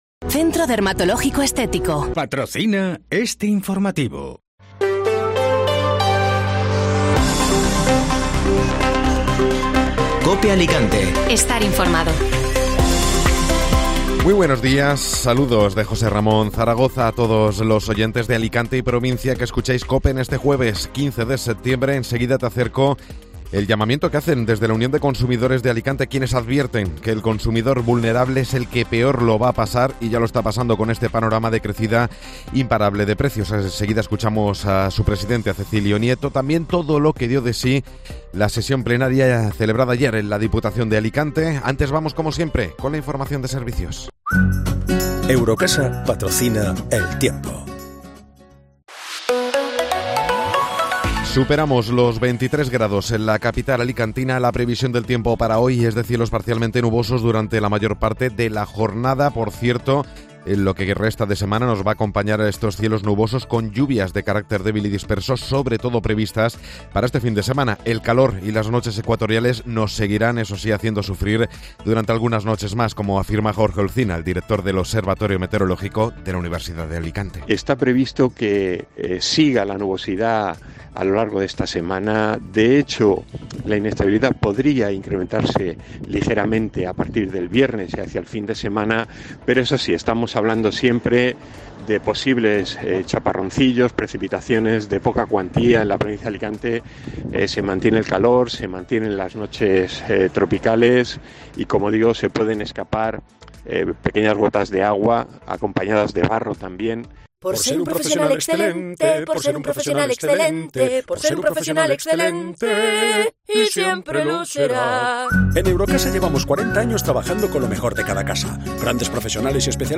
Informativo Matinal (Jueves 15 de Septiembre)